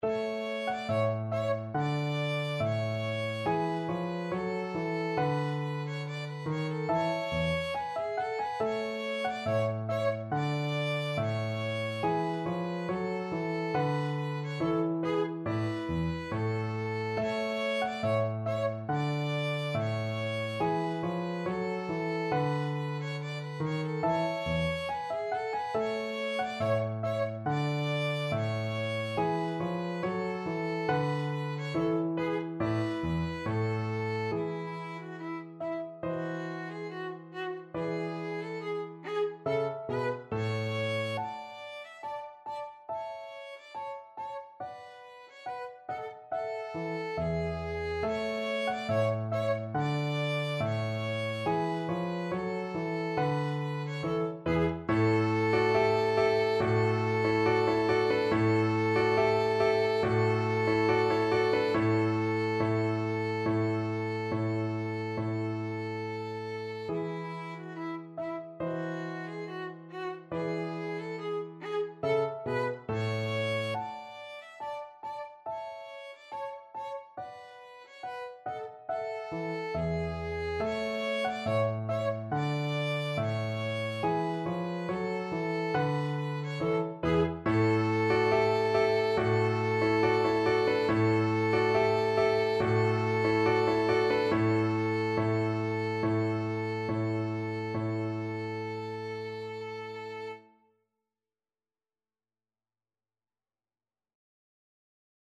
Classical Haydn, Franz Josef St. Anthony Chorale Violin version
Violin
2/4 (View more 2/4 Music)
A major (Sounding Pitch) (View more A major Music for Violin )
Classical (View more Classical Violin Music)